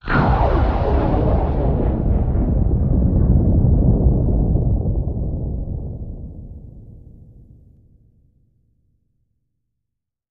Water; Underwater Gunshot Explosion, With Reverb.